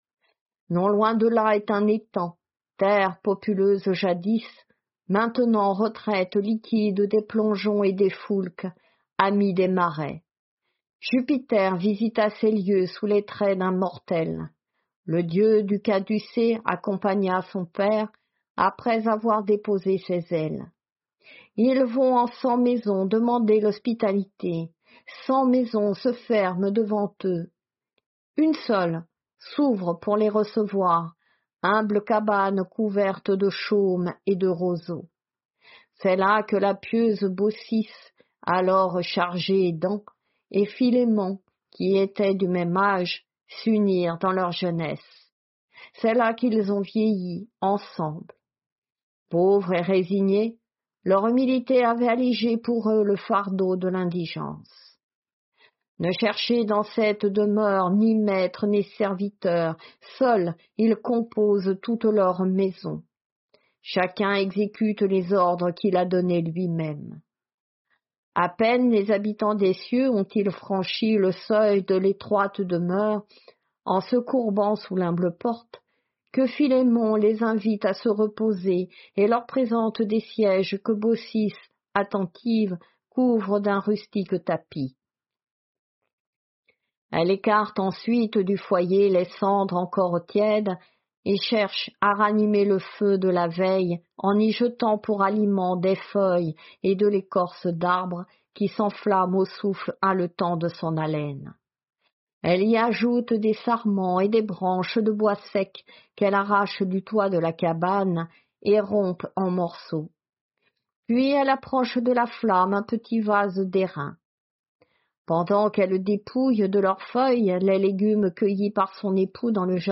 Lecture de la métamorphose de Baucis et de Philémon · GPC Groupe 1